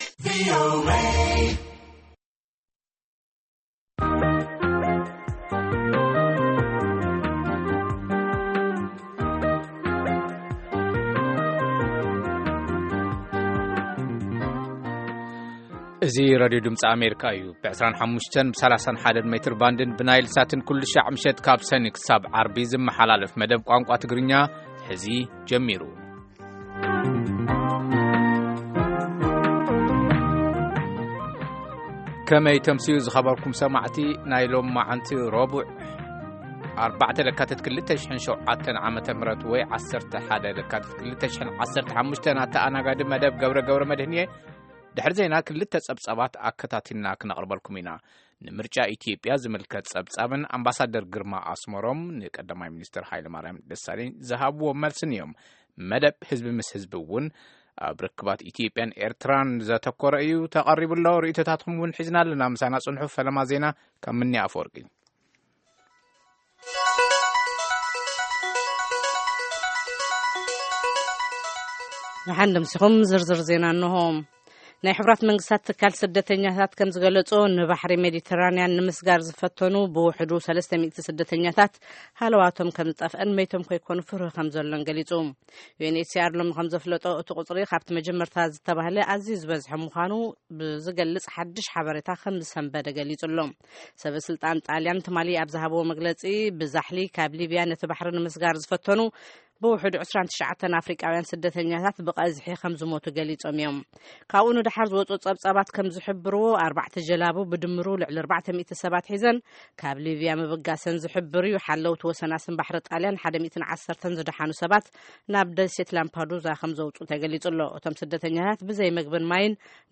Tigrigna News
ትግርኛ ሃገራዊ ቋንቋ 5.6 ሚልዮን ህዝቢ ዘለዋ ኤርትራ`ዩ። ትግርኛ፡ ካብ 11 ኽልታት ኢትዮጵያ ሓንቲ ኣብ ዝኾነት ኽልል ትግራይ ይዝረብ። ትግርኛ ካብ መንጎ ቐዳሞት ቋንቋታት ኢትዮጵያ ሓደ ምዃኑ ይእመን። ድምጺ ኣመሪካ ኣገልግሎት ትግርኛ ፡ ካብ ሰኑይ ክሳብ ዓርቢ ነናይ 30 ደቓይቕ ፕርግራም ይፍኑ 1900 to 1930 UTC/. ፈነወ ትግርኛ ብናይ`ቲ መዓልቲ ዓበይቲ ዜና ይጅምር፡ ካብ ኤርትራን ኢትዮጵያን ዝረኽቦም ቃለ-መጠይቓትን ሰሙናዊ መደባትን የኸትል ሰሙናዊ መደባት ረቡዕ፡ ህዝቢ ምስ ህዝቢ